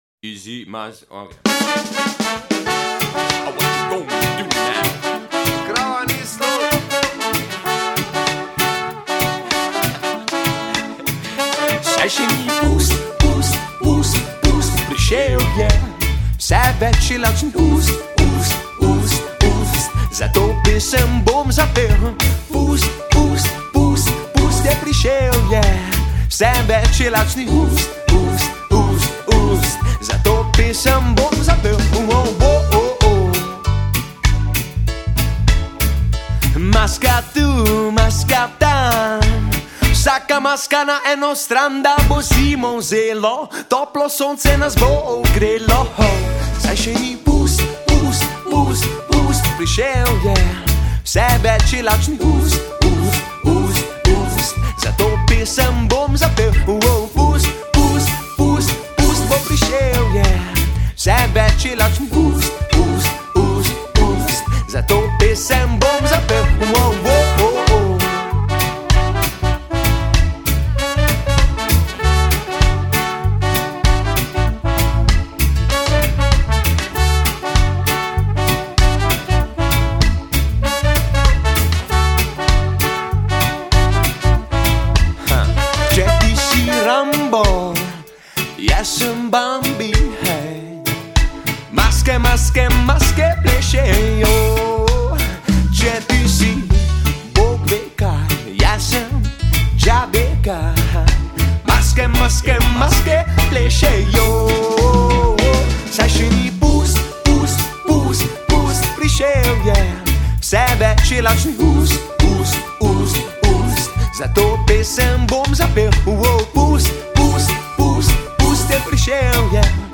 vokal
klaviature
saksofon
bobni
reggae skupina